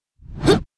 swordman_attack11.wav